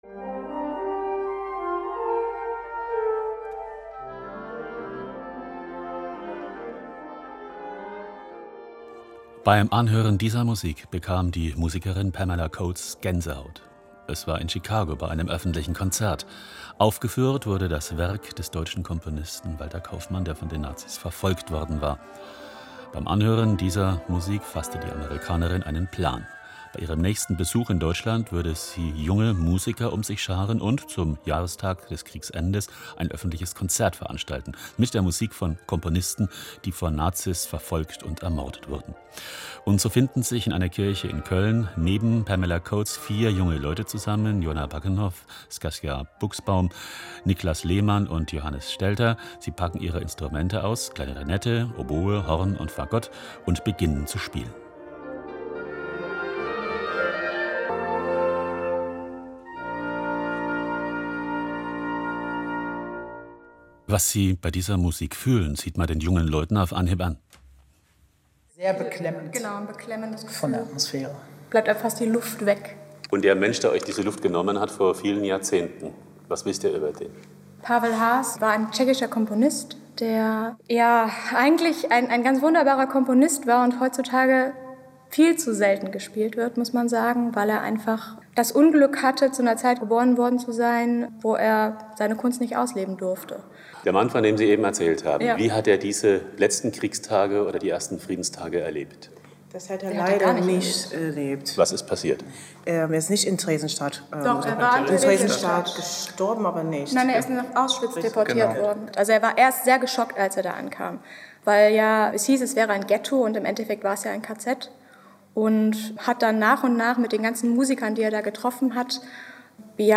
„Neutöner“ erklangen in der Ahrweiler Synagoge- Ensemble „VivazzA“ brillierte mit ausgereifter Präsenz